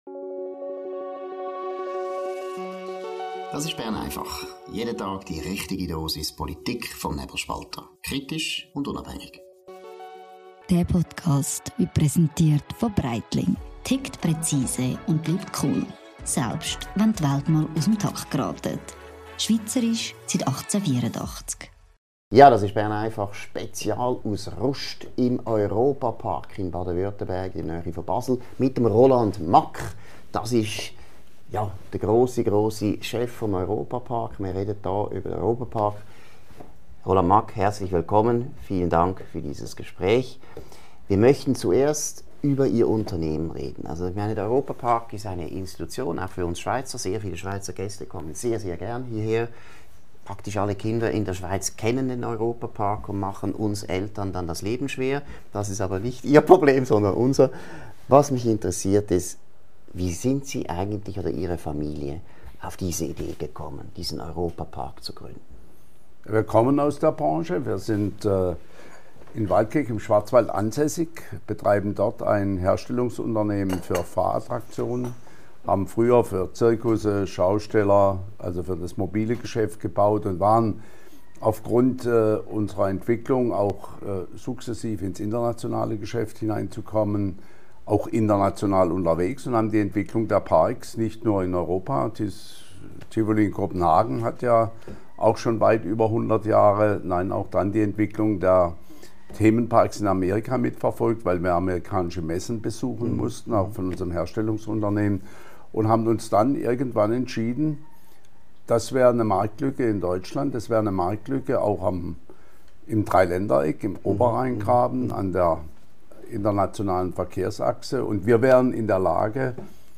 Ein ausführliches Interview mit Europa-Park-Gründer Roland Mack über Unternehmertum, den Zustand von Deutschland, die Leistungen von Bundeskanzler Friedrich Merz und wieso die Schweiz einiges besser macht.